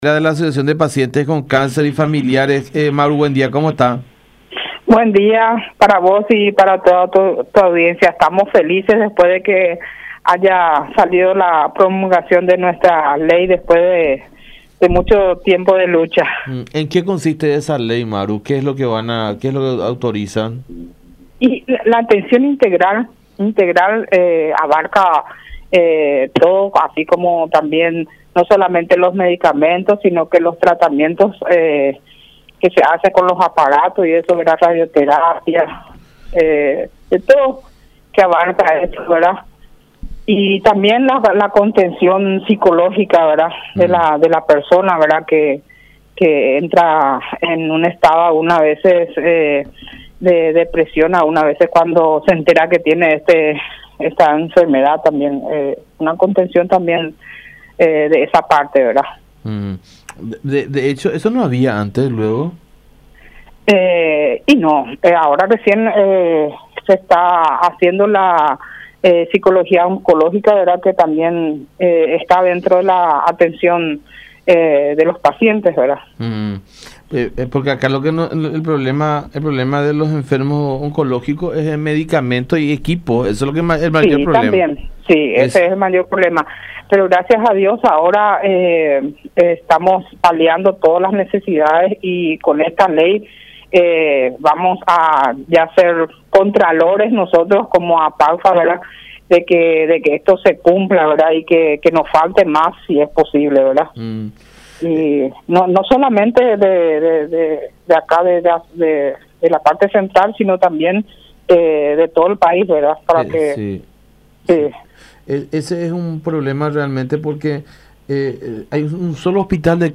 en comunicación con La Unión.